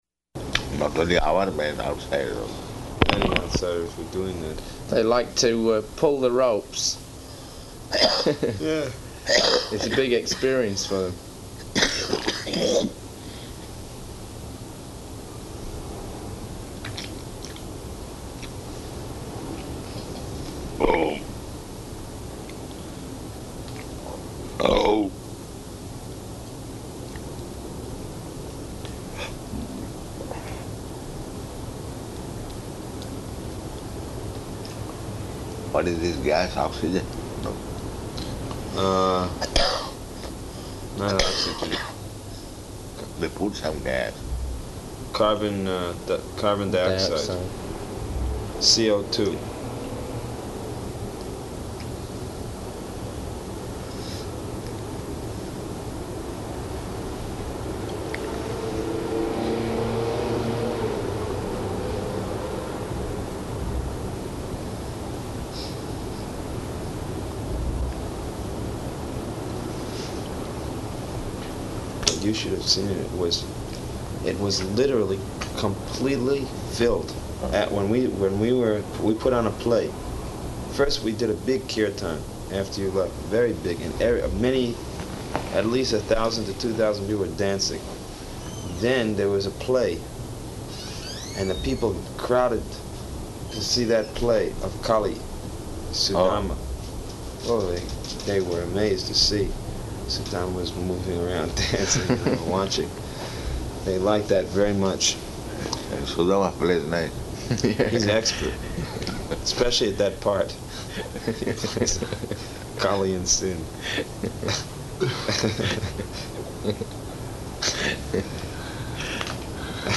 Room Conversation
Location: New York